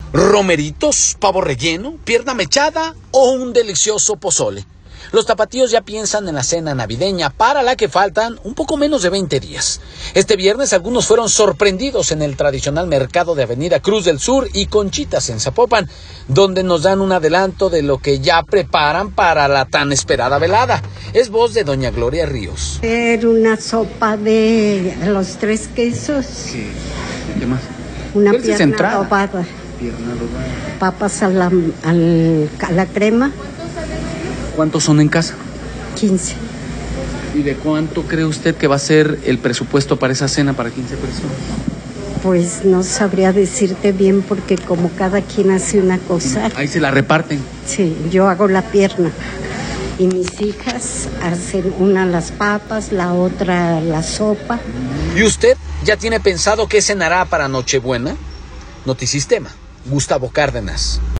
Romeritos, pavo relleno, pierna mechada o un delicioso pozole, los tapatíos ya piensan en la cena navideña para la que faltan un poco menos de 20 días. Este viernes algunos fueron sorprendidos en el tradicional Mercado de avenida cruz del Sur de conchitas en Zapopan, donde nos dan un adelanto de lo que prepararán para la tan esperada velada.